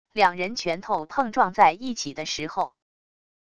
两人拳头碰撞在一起的时候wav音频